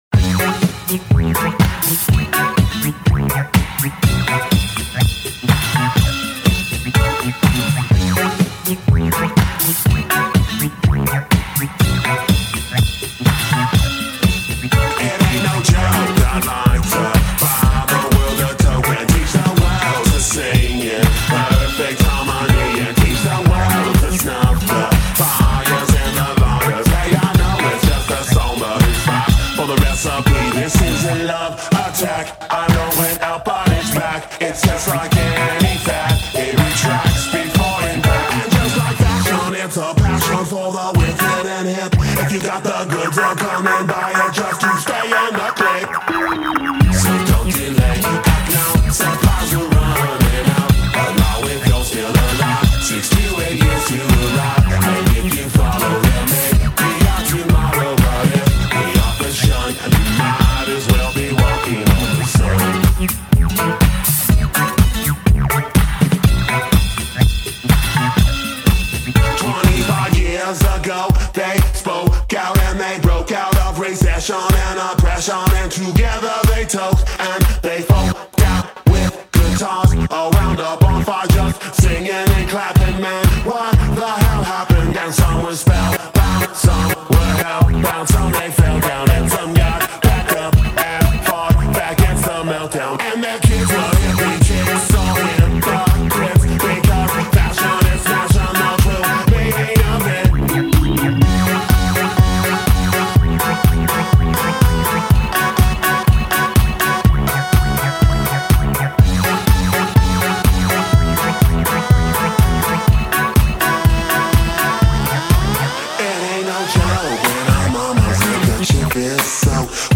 this is the best smash up yet
remix